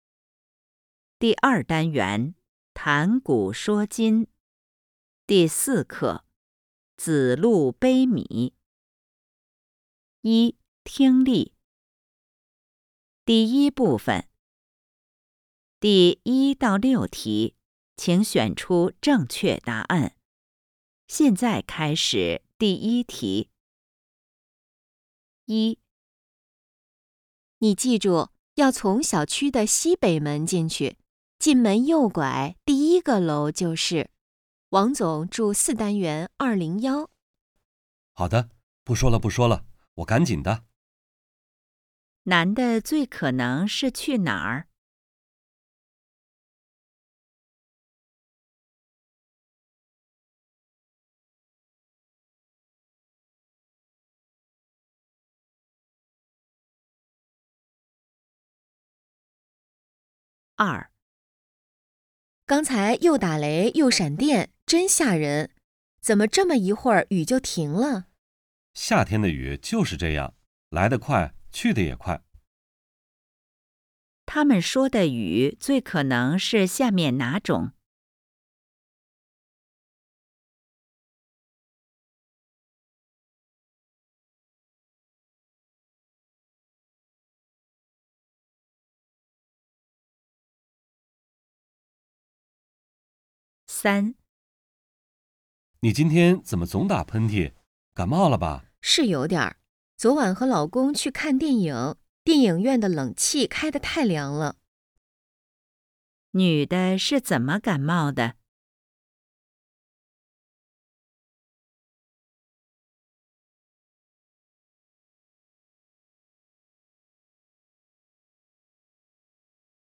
一、听力